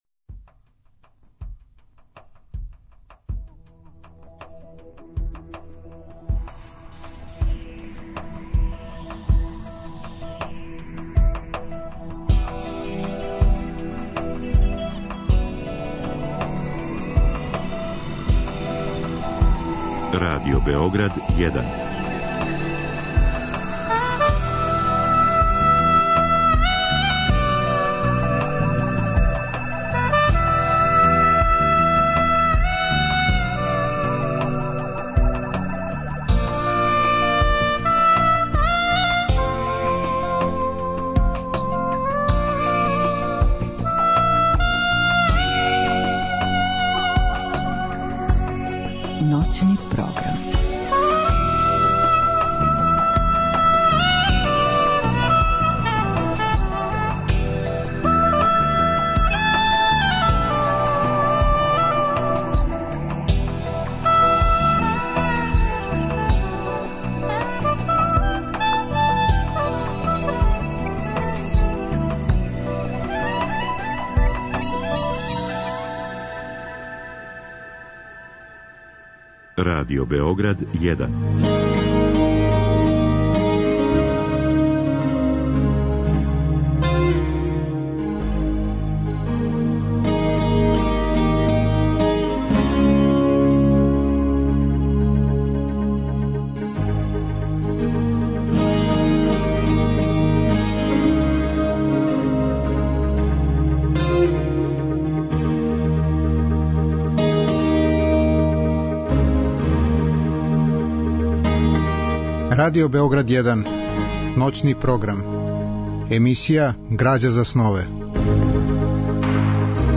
Разговор и добра музика требало би да кроз ову емисију и сами постану грађа за снове.
У другом делу емисије, од два до четири часa ујутро, слушаћемо одабране делове радио-драма које су рађене по текстовима античких трагедија и комедија.